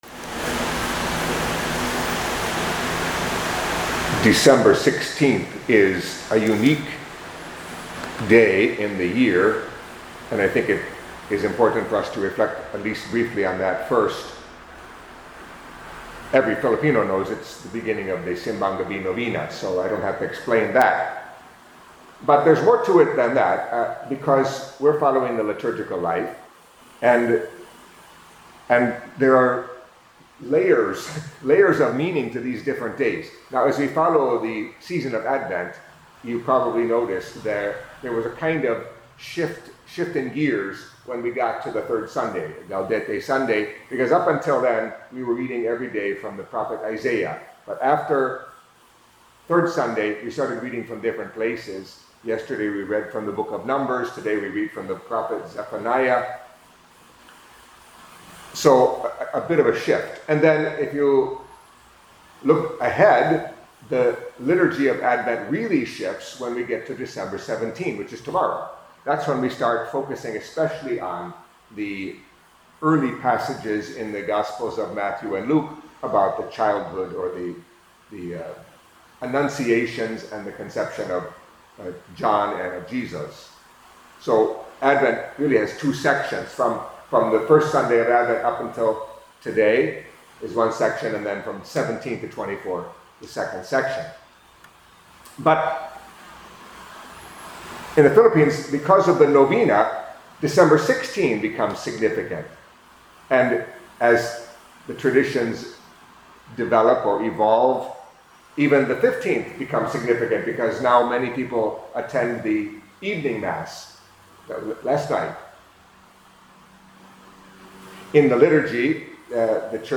Catholic Mass homily for Tuesday of the Third Week of Advent